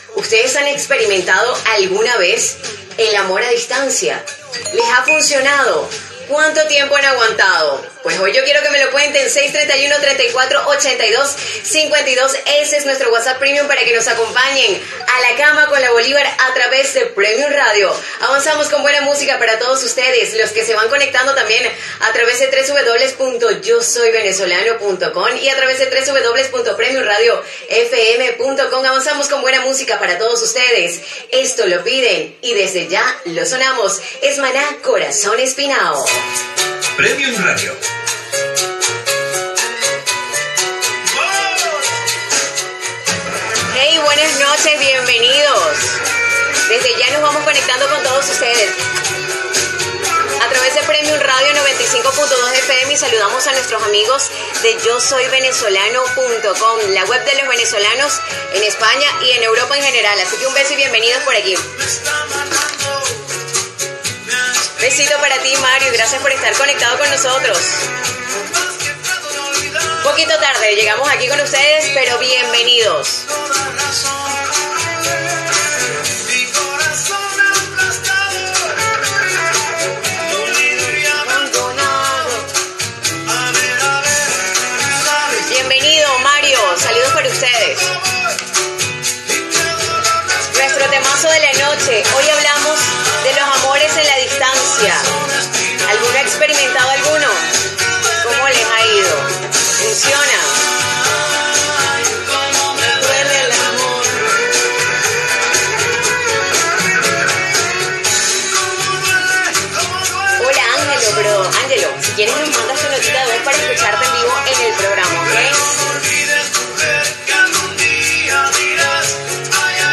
Comentari sobre l'amor a distància, formes de contactar amb el programa, indicatiu, tema musical i invitació a participar en el programa
Entreteniment
FM